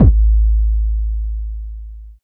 51 KICK 4.wav